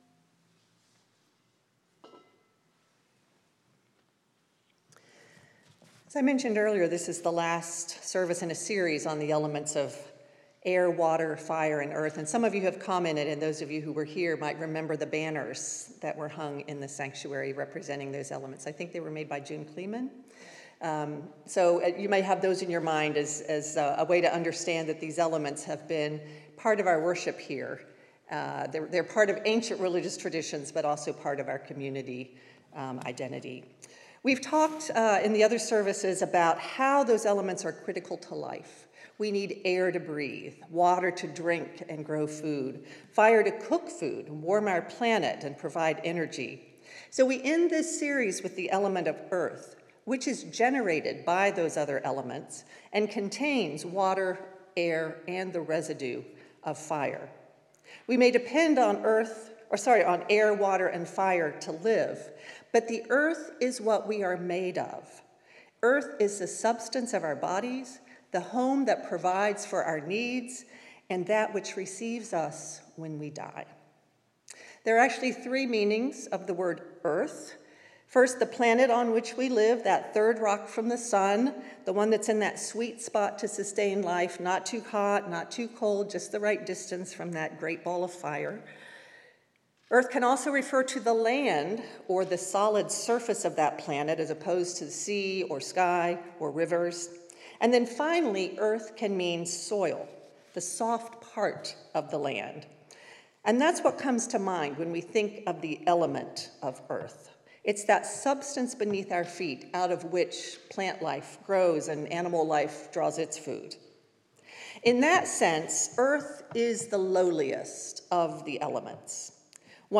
In this season when plants die off and nature prepares to rest for the winter, we focus on the earth as the source of all life and to which all life returns. The final service in a series celebrating the elements of air, water, fire, and earth, our worship will reference Samhain and harvest rituals and will end outside with a short blessing for our church garden.